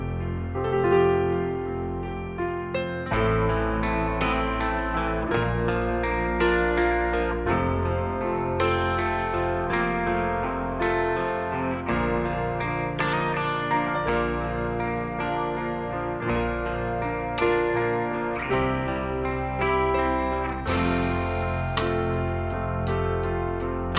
Minus Lead And Solo Guitar Rock 6:35 Buy £1.50